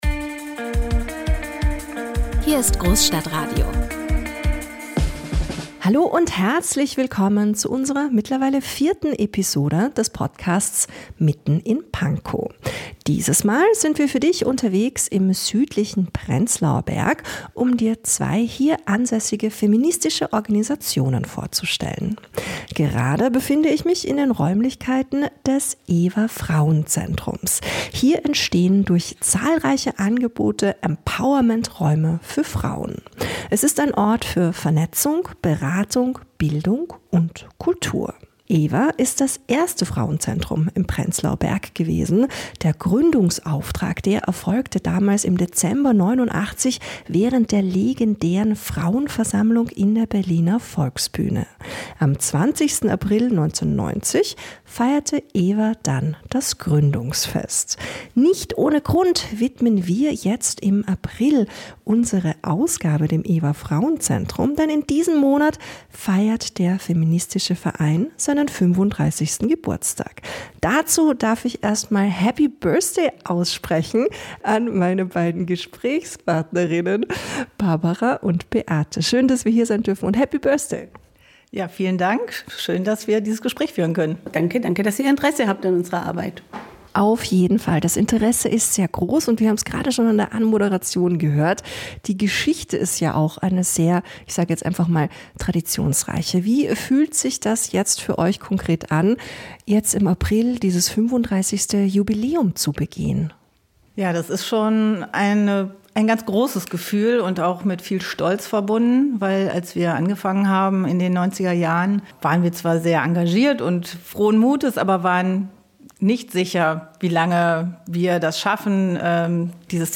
Beschreibung vor 9 Monaten In Folge 4 der neuen Großstadtradio-Reihe "Mitten in Pankow" waren wir im Prenzlauer Berg unterwegs, um über die Arbeit von zwei feministischen Vereinen zu berichten.